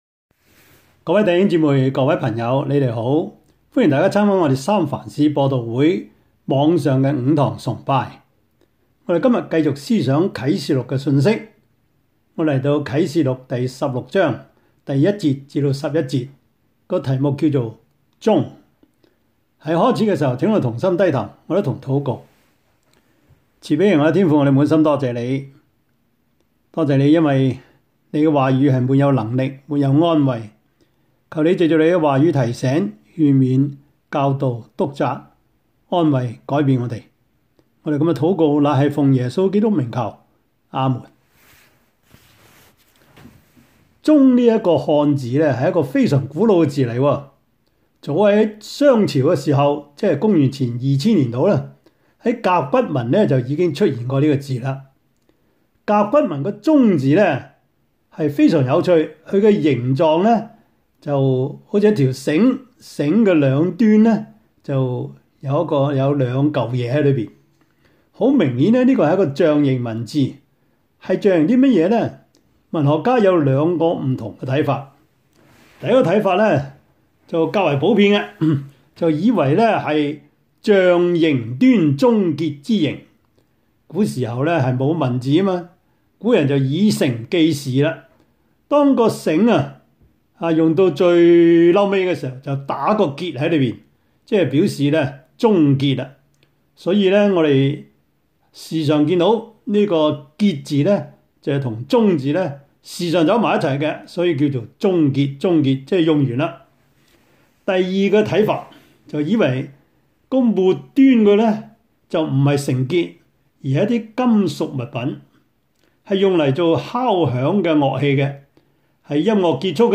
Service Type: 主日崇拜
Topics: 主日證道 « 你們還是不明白嗎?